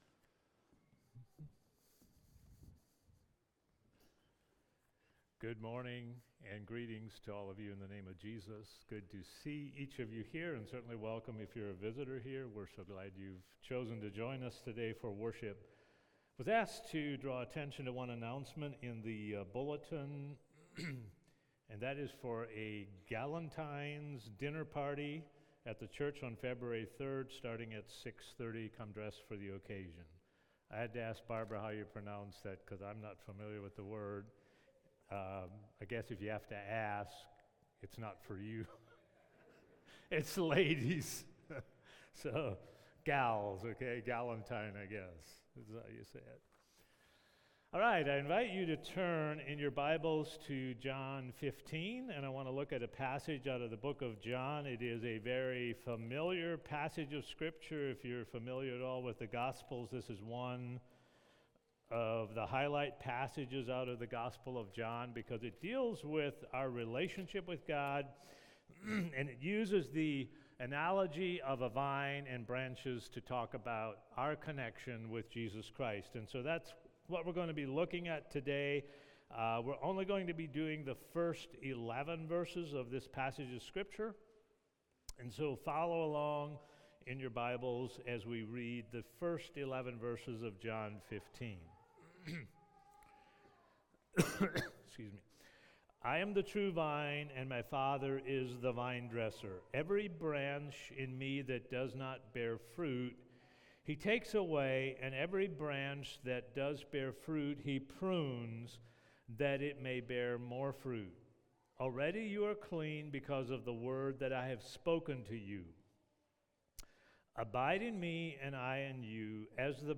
Sermons | Christian Life Mennonite